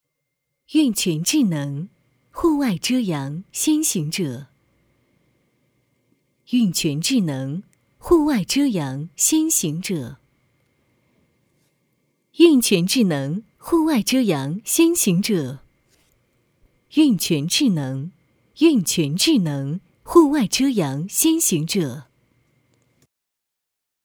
运泉智能 - 女3号 梦梦 - 双讯乐音旗舰店